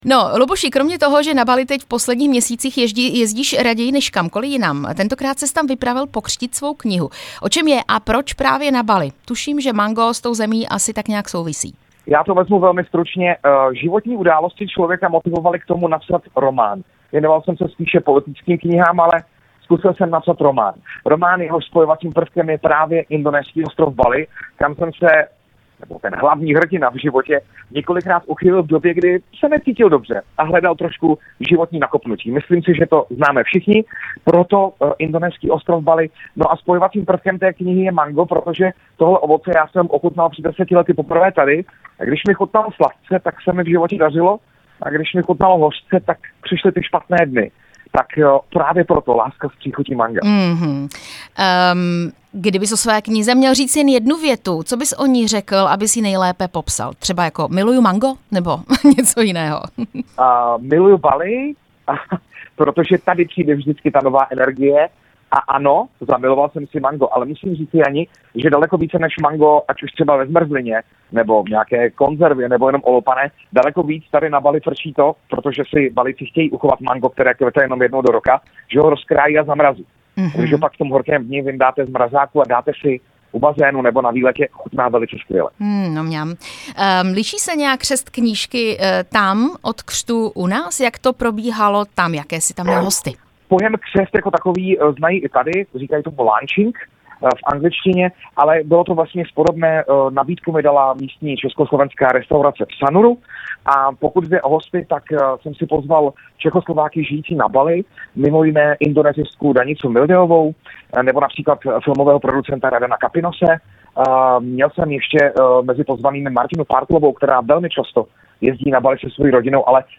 Vysílání z Bali o nové knize Láska s příchutí manga